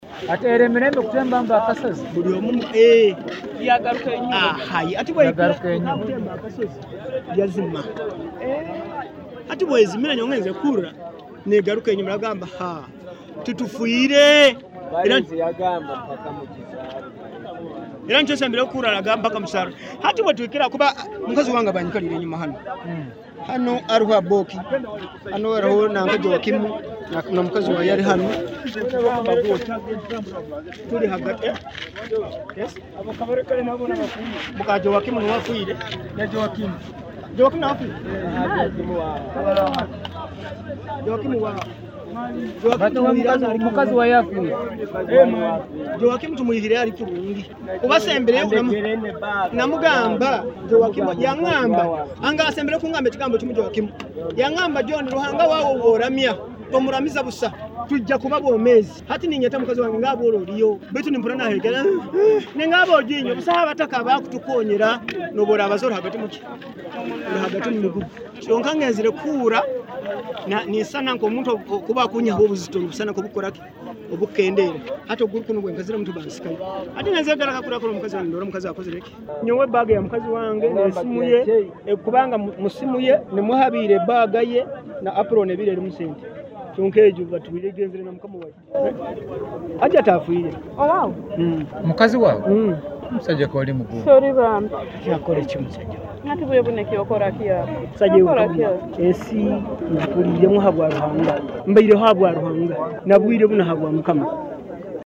Voices from scene